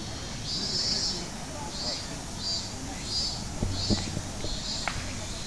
ミンミンゼミの声(119KB wave)